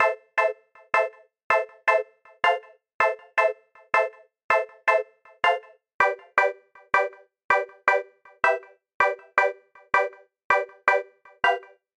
描述：ano循环，可用于Pop goes to heaven2...也许也可用于Hip Hop歌曲。
Tag: 80 bpm Pop Loops Piano Loops 2.02 MB wav Key : A